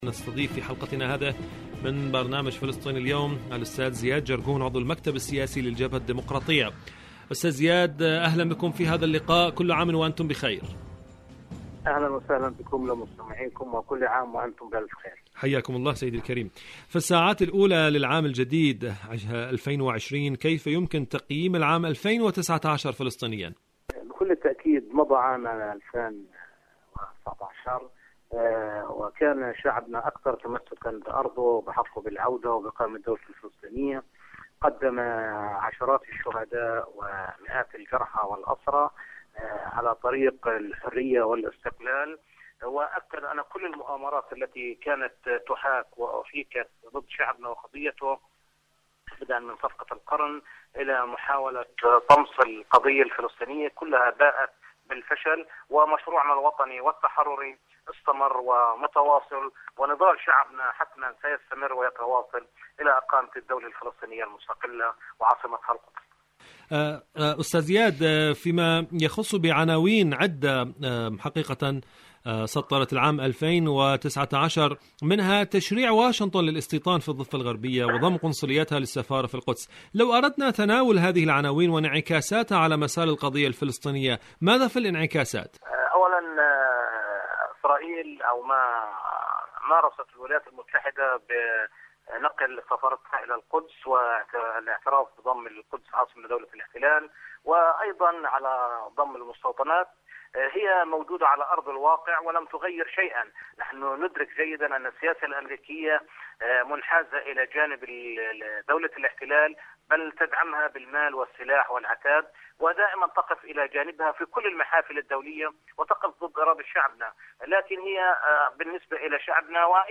مقابلة
إذاعة طهران-فلسطين اليوم: مقابلة إذاعية